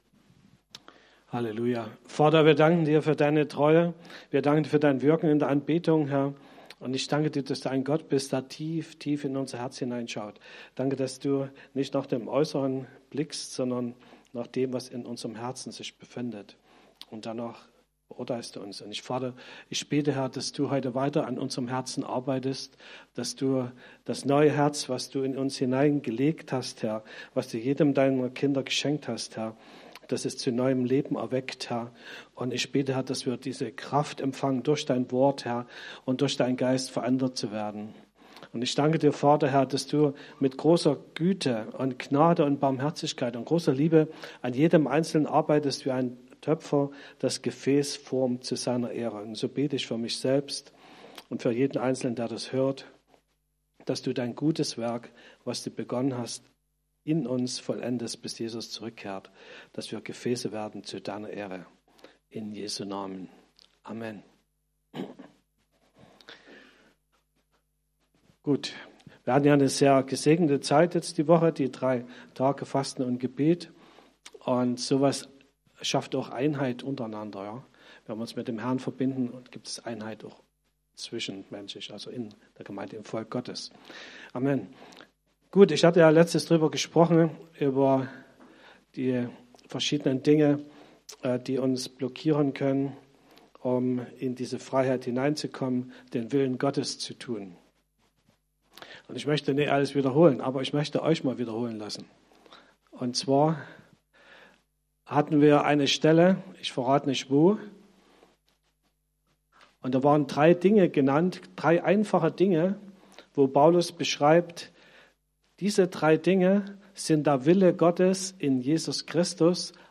Predigten chronologisch sortiert